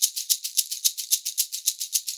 110 SHAKERS4.wav